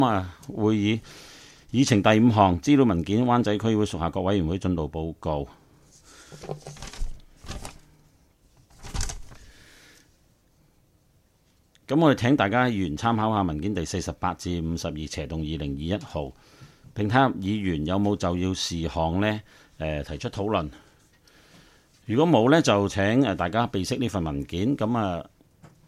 区议会大会的录音记录
湾仔民政事务处区议会会议室